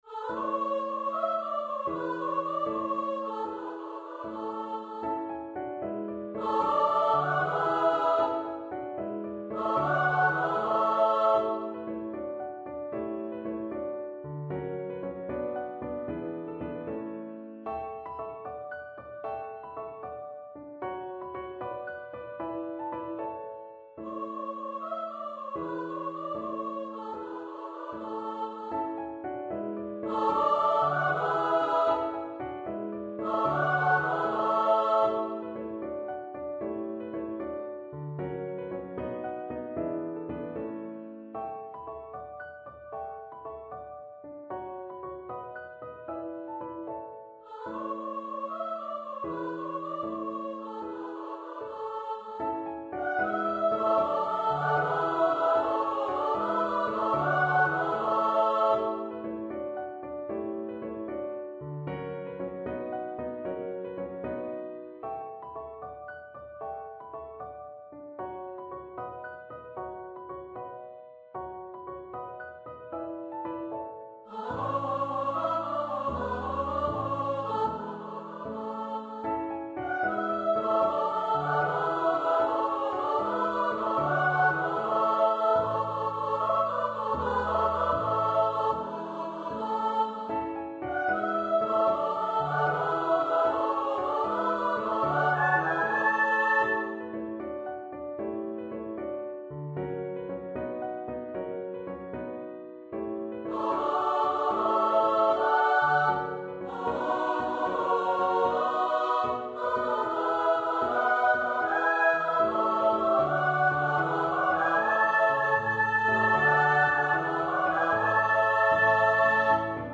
for upper voice choir and piano
for SSA choir with piano accompaniment